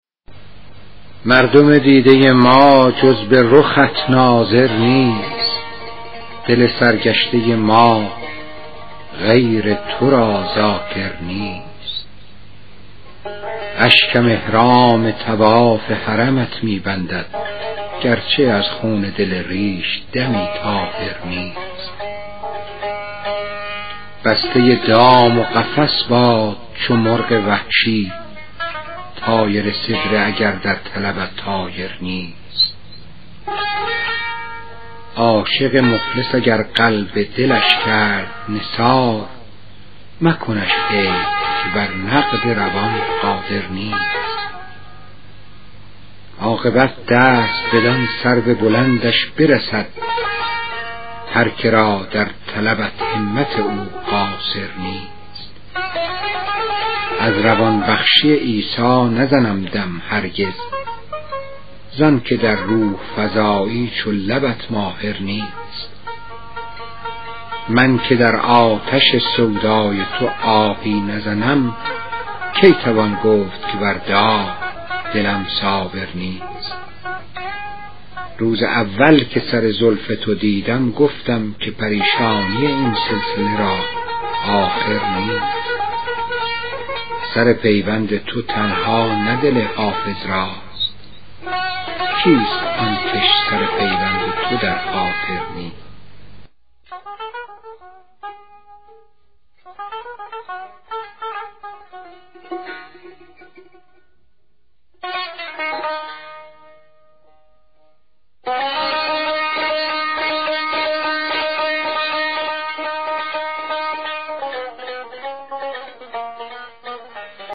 دکلمه غزل شماره 70 دیوان حافظ شیرازی